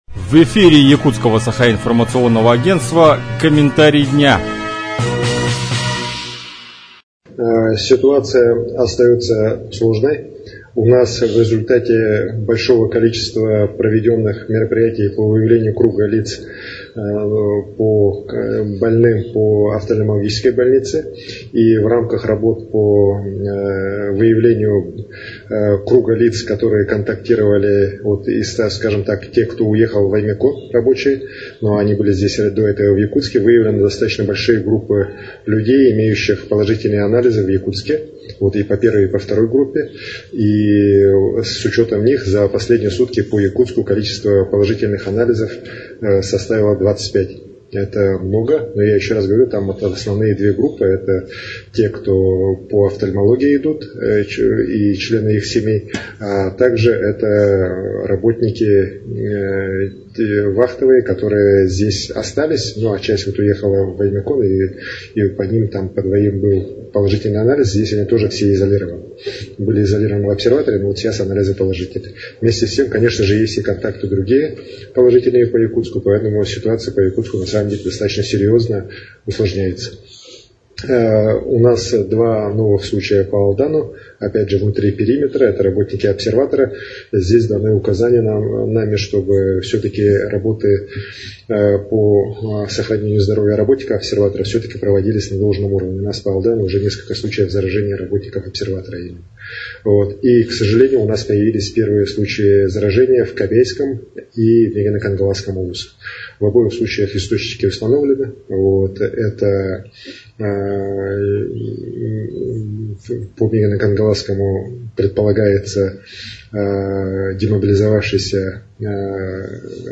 Какова обстановка в Якутии на 30 апреля, рассказал глава региона Айсен Николаев.